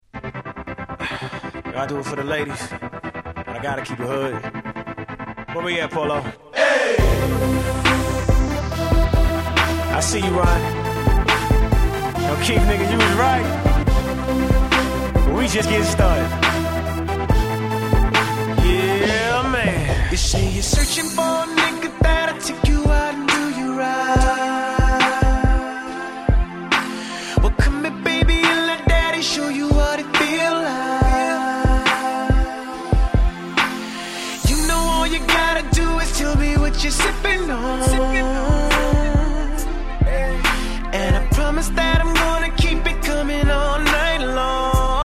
08' Super Hit R&B !!
この頃からこの曲みたいにBPMは遅いけどメインの時間にもかけられる〜みたいな曲が増えましたよね。